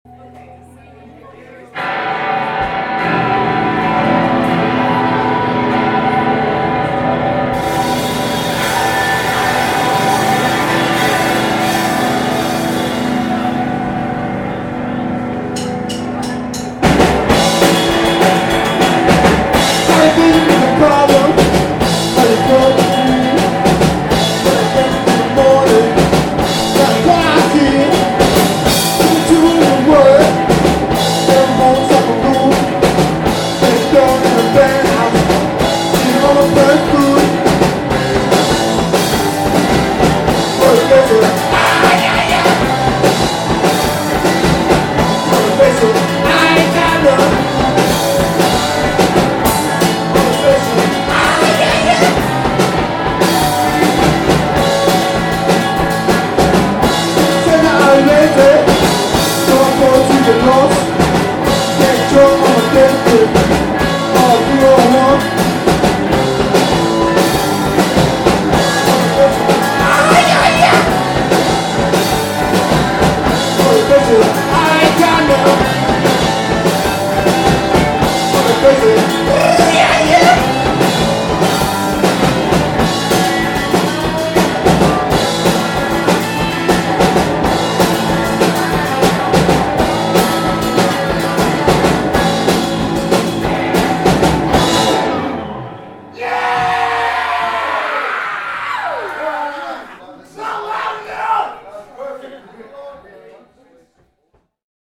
Local punk band